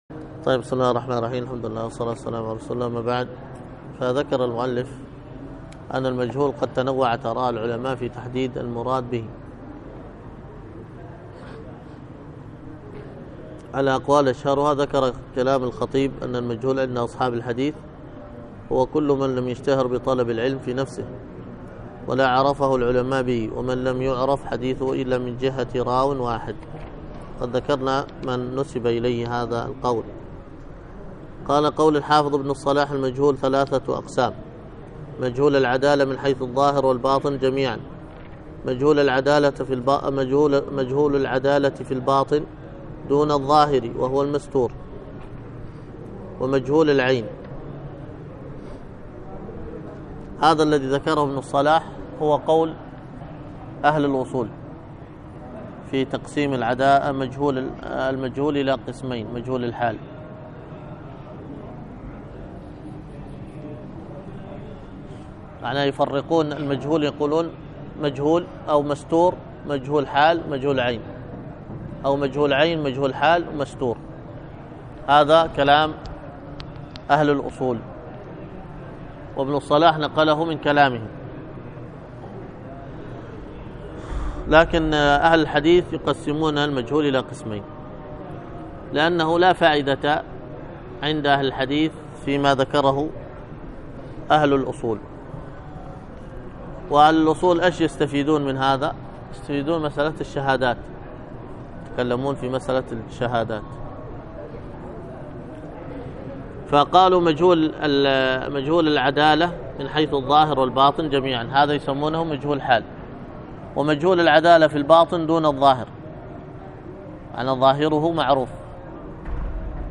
الدرس